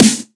edm-snare-37.wav